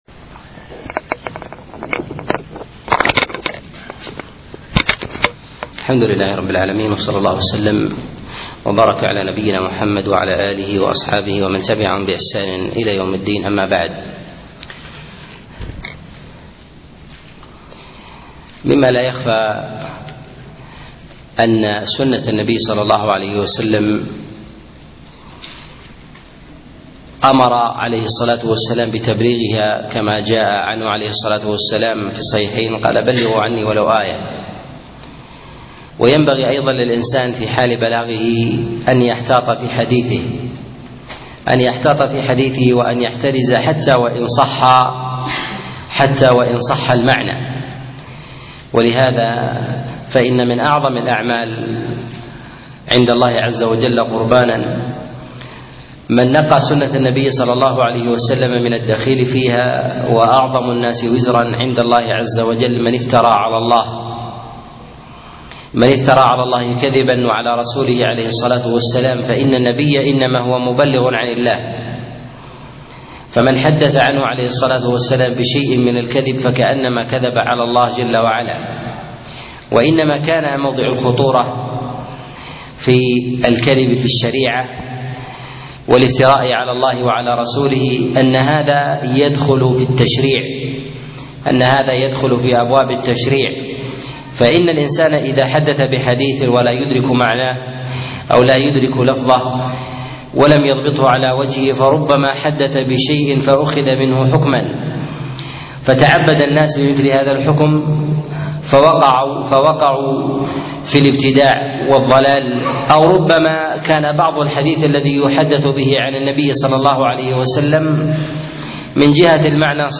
الأحاديث المعلة في الحج الدرس 1